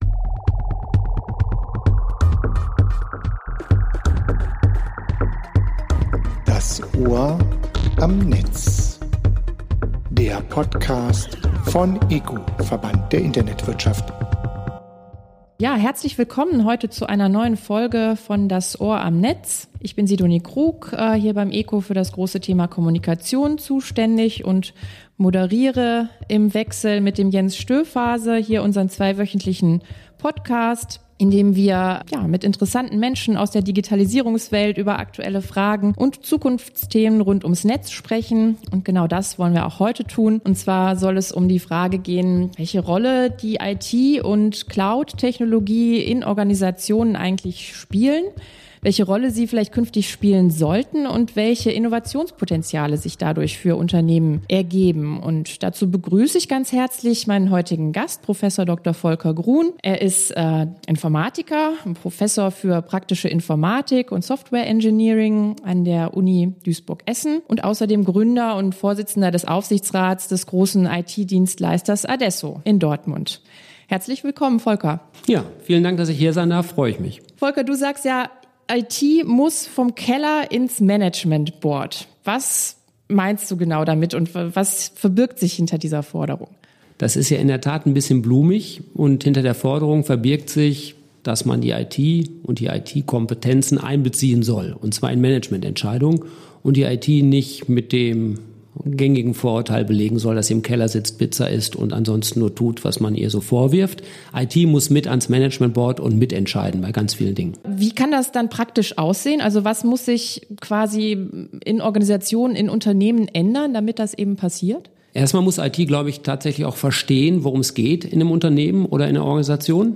New School of IT: Interview